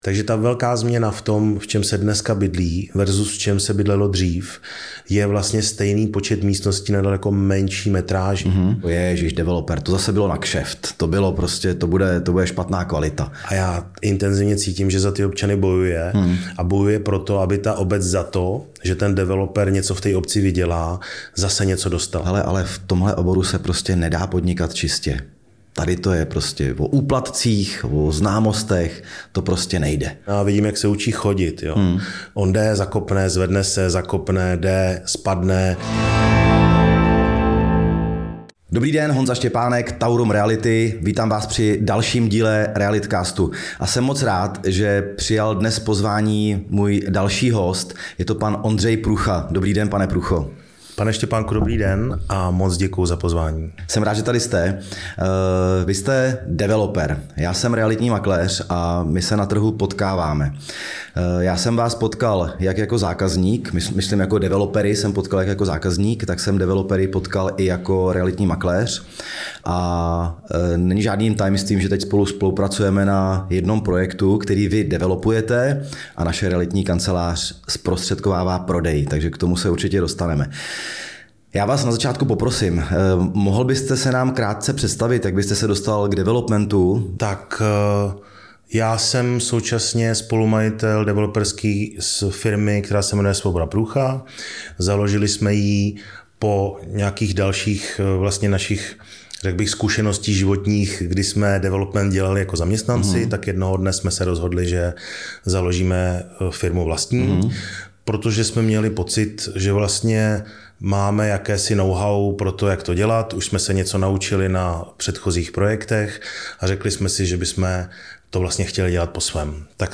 V rozhovoru